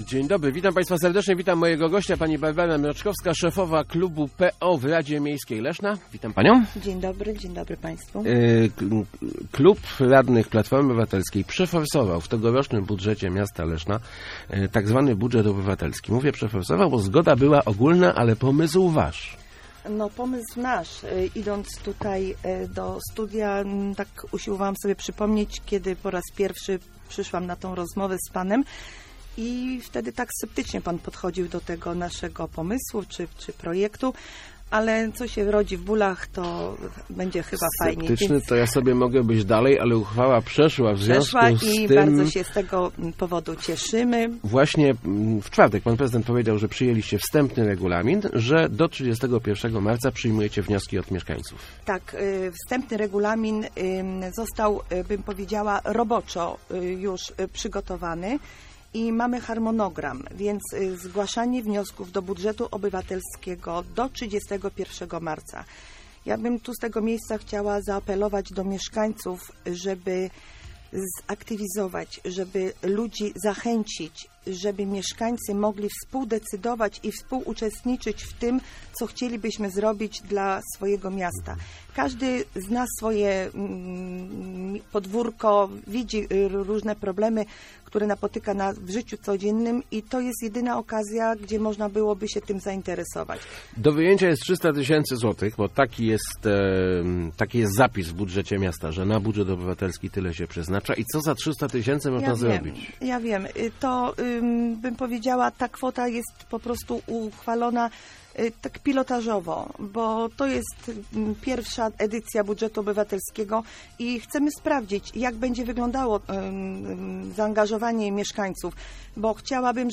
Chcemy, żeby były to trwałe, infrastrukturalne projekty - mówiła w Rozmowach Elki Barbara Mroczkowska, szefowa klubu PO w Radzie Miejskiej Leszna o pierwszej edycji "budżetu obywatelskiego". Wnioski podpisane przez co najmniej 20 mieszkańców mogą być składane do końca marca.